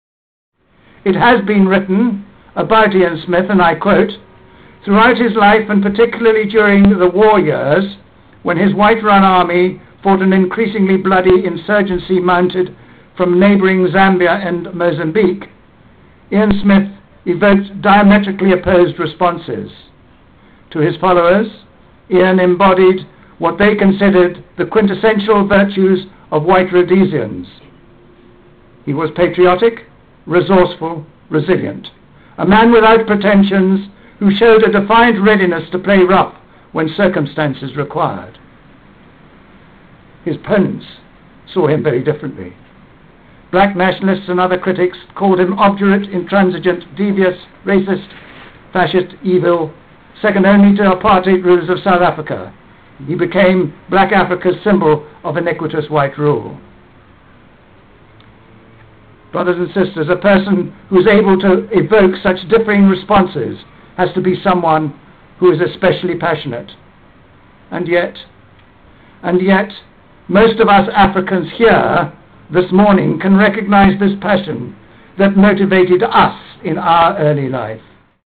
This historic recording was made at the Memorial Service held in the UK on 1st December 2007.
Service.wma